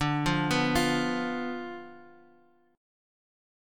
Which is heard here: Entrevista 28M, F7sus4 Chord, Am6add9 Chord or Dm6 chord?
Dm6 chord